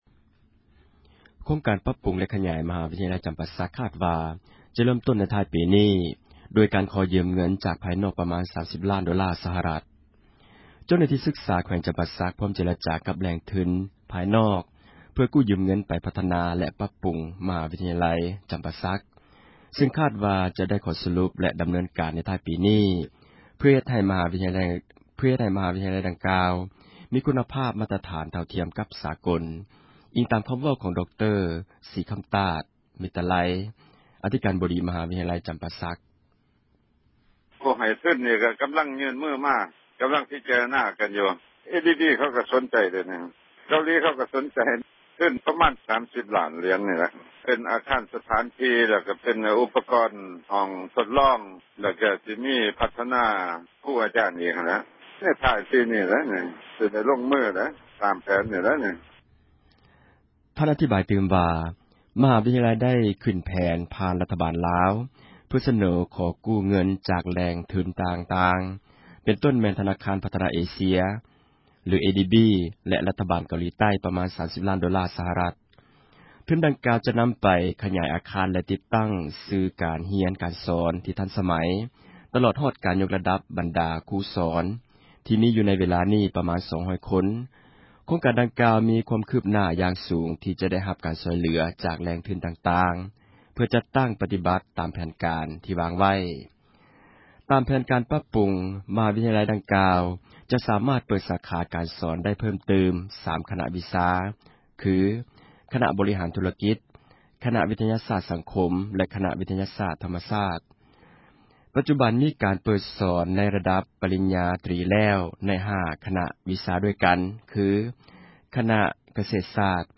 ຄ.ກ ປັບປຸງ ມະຫາວິທຍາລັຍ ຈໍາປາສັກ ຄາດຈະເຣີ້ມ ທ້າຍປີ 2008 — ຂ່າວລາວ ວິທຍຸເອເຊັຽເສຣີ ພາສາລາວ